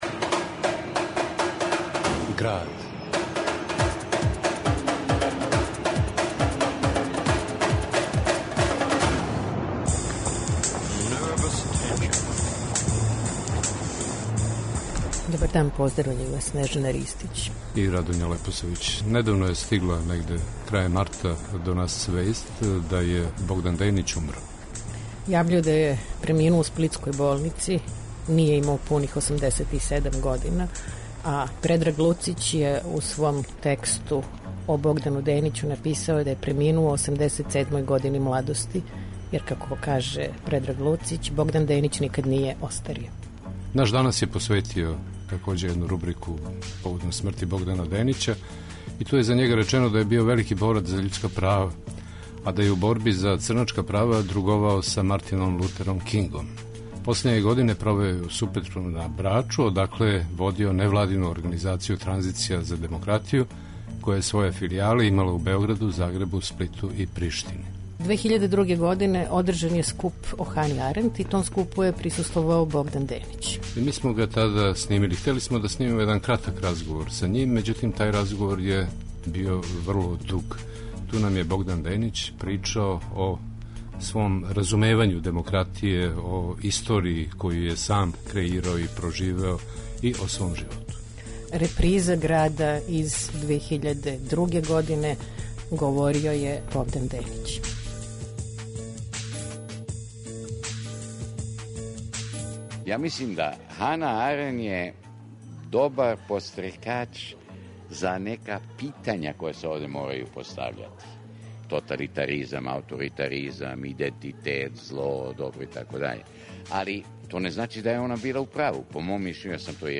Замолили смо га за кратак разговор... а трајао је више од сата.